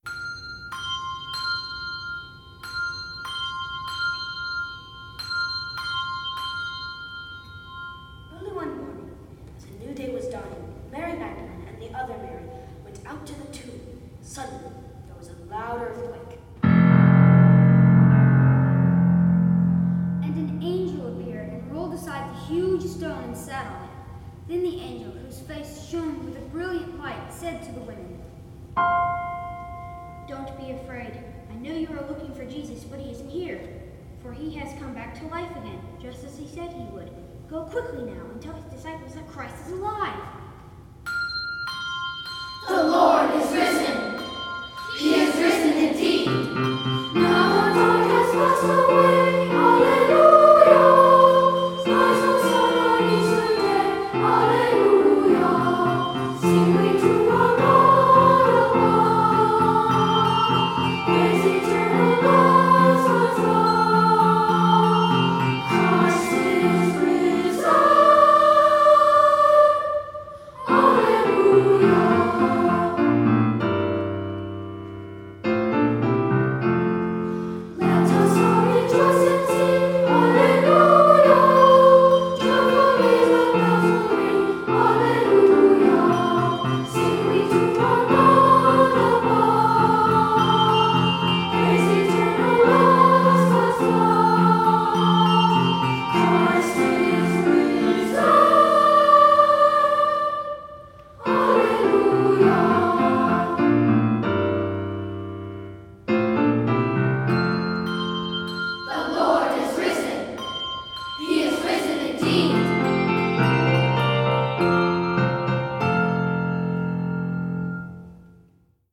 Composer: 15th Century Bohemian Carol
Voicing: Unison and Piano